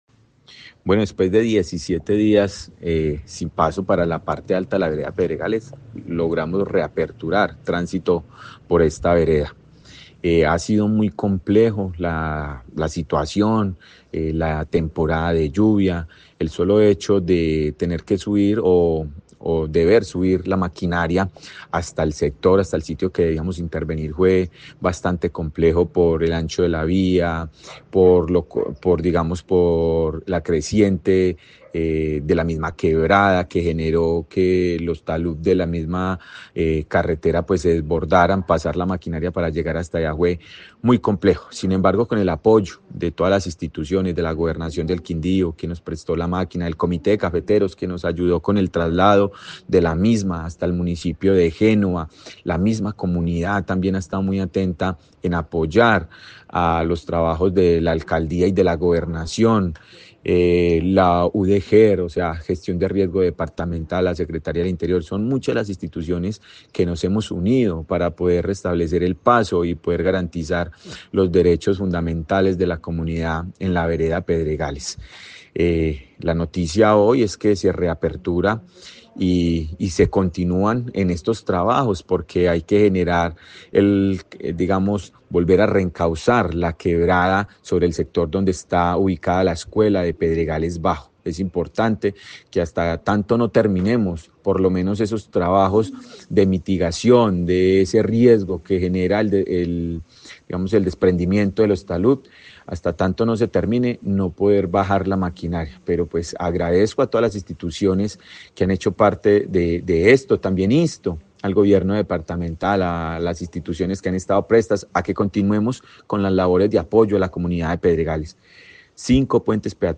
Alcalde de Génova, Quindío, Diego Fernando Sicua